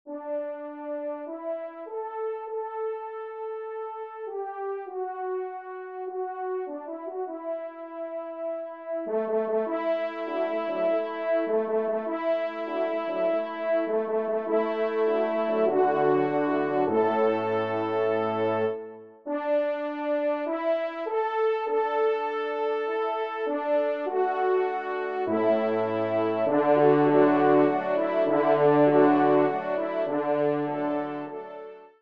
Genre :  Divertissement pour Trompes ou Cors & Orgue
Pupitre 4° Trompe